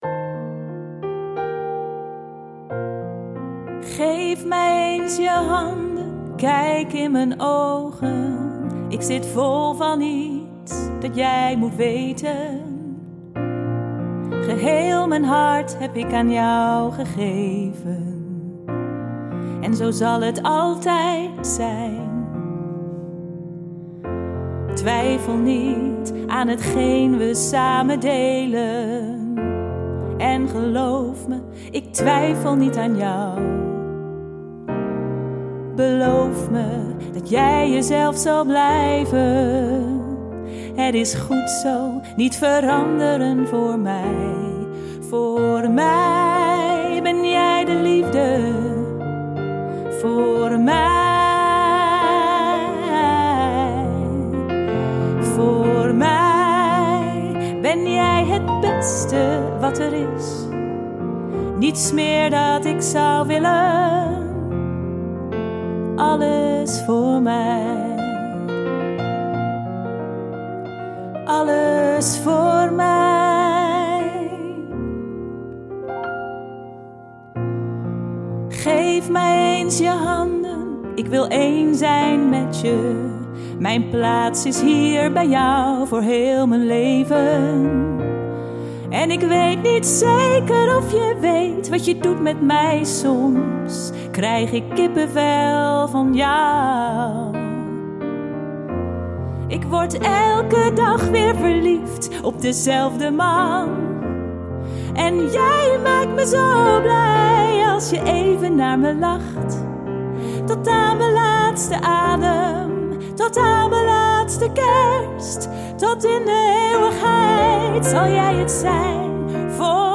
Pop/easylistening